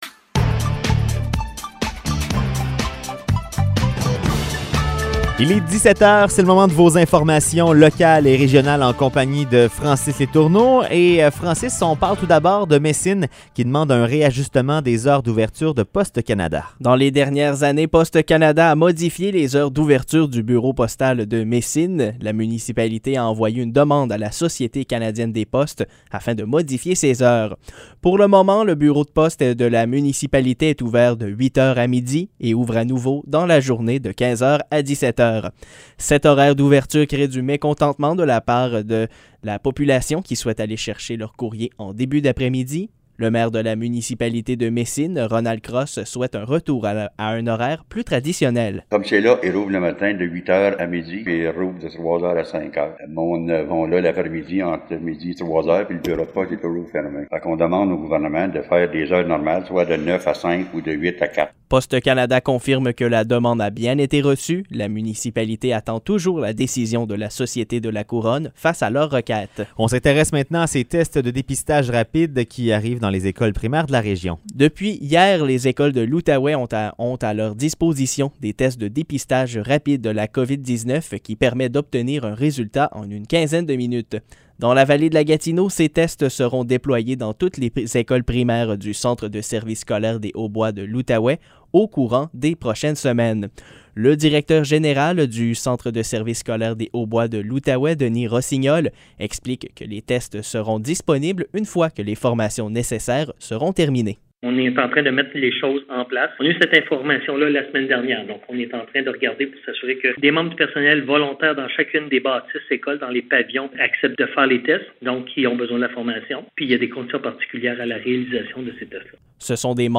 Nouvelles locales - 28 septembre 2021 - 17 h